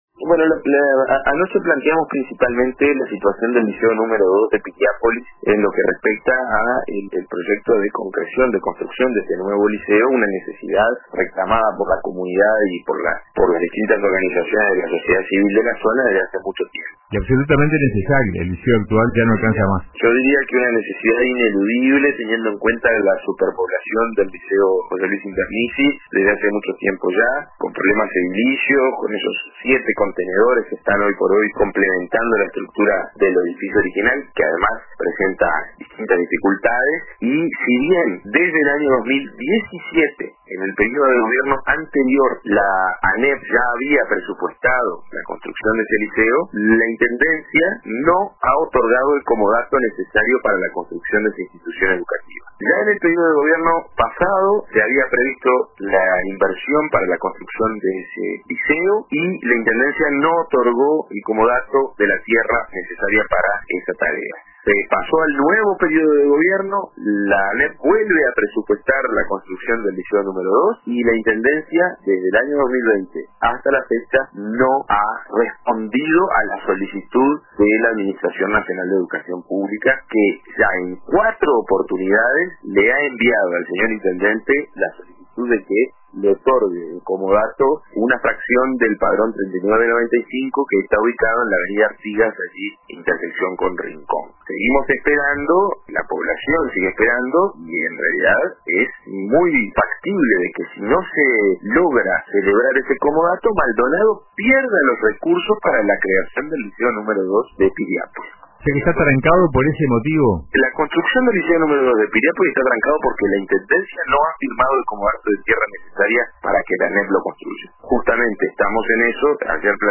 El edil del Frente Amplio, Joaquín Garlo, en una entrevista con el programa RADIO CON TODOS de RBC, expresó su preocupación por la paralización de la construcción del Liceo número dos de Piriápolis.